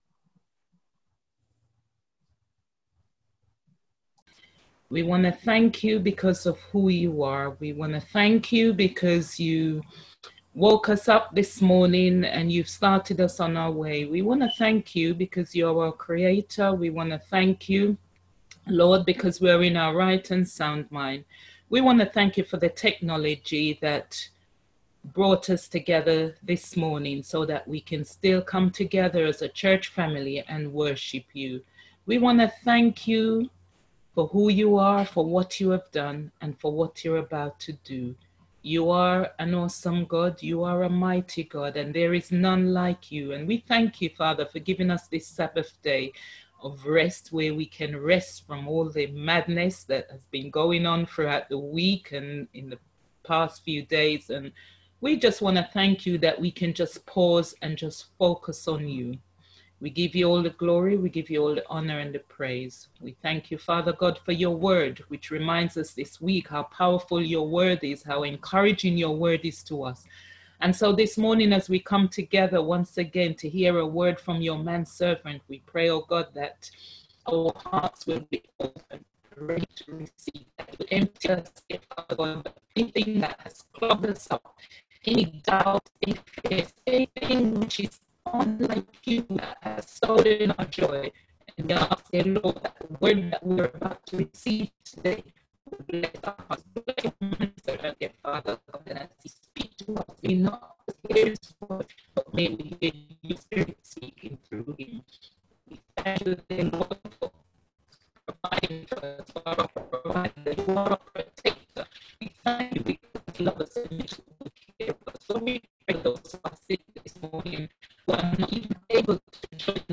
on 2024-02-14 - Sabbath Sermons